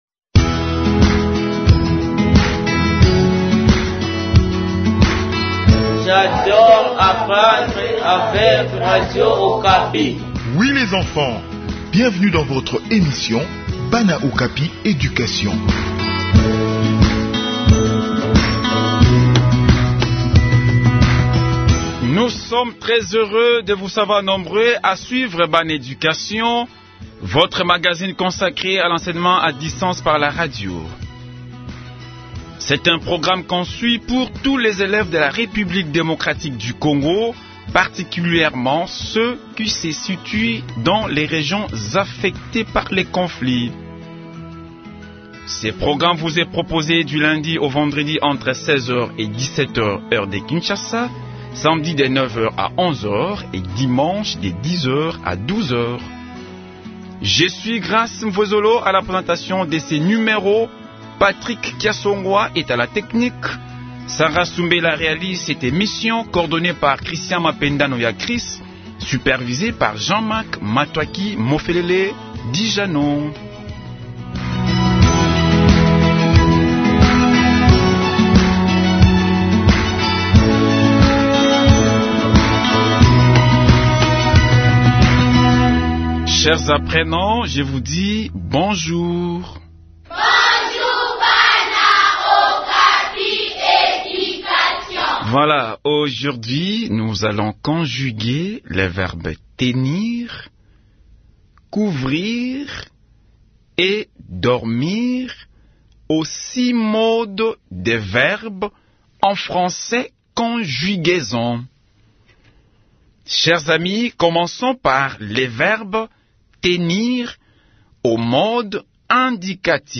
Enseignement à distance : leçon de conjugaison des verbes Tenir et Courir